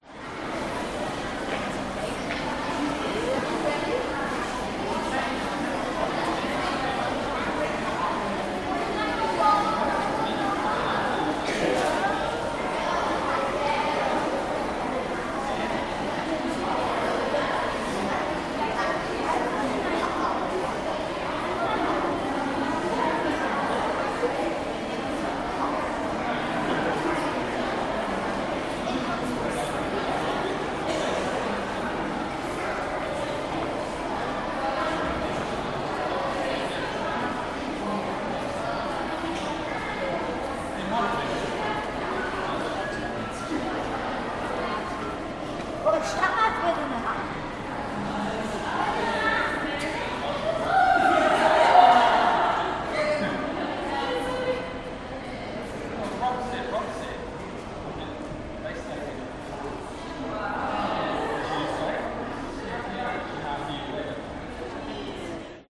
Художественная галерея Нового Южного Уэльса, оживленный вестибюль, шум детей и разговоры, атмосфера Сиднея, Австралия